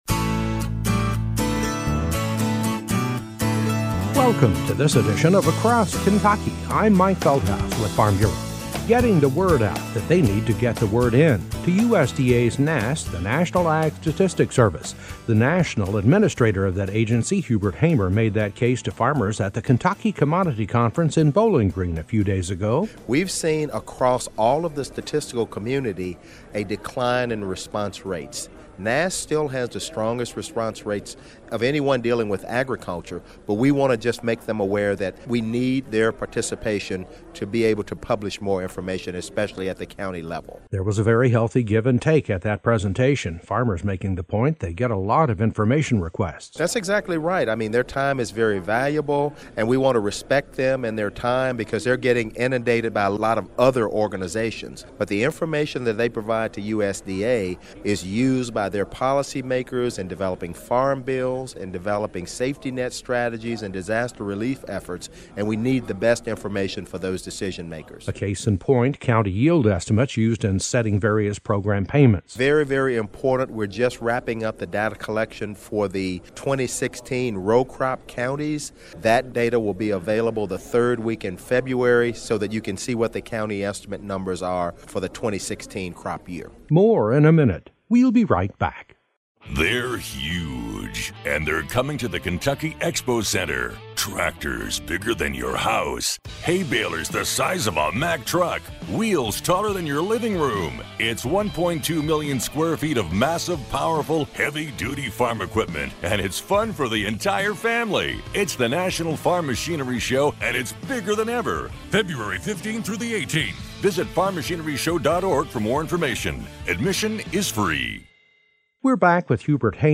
A report with the administrator of USDA’s National Agriculture Statistics Service, Hubert Hamer, who says that agency, and others, have seen a decline in the response rate to their survey requests throughout the year. He was making a pitch to farmers at the recent Ky Commodity Conference to be sure and respond to their requests as most often the information they receive is utilized in safety net programs to help farmers.